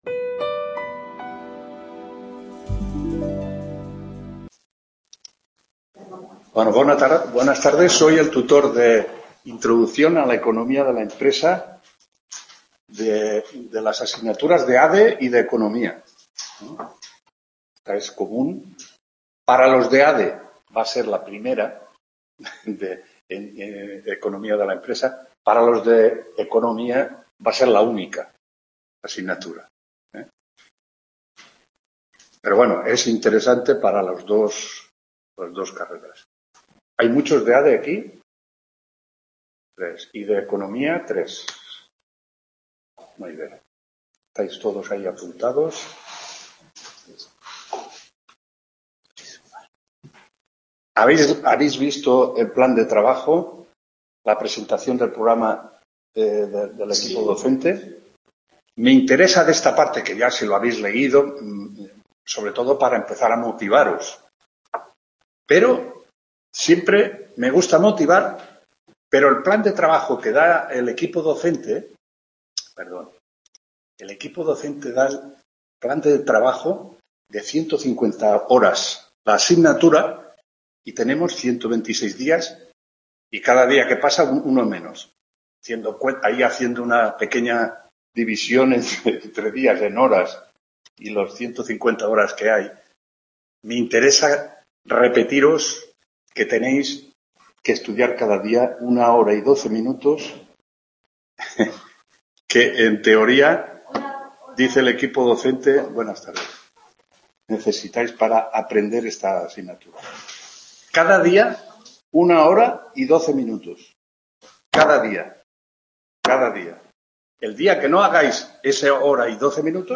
1ª TUTORÍA AVIP INTRODUCCIÓN A LA ECONOMÍA DE LA… | Repositorio Digital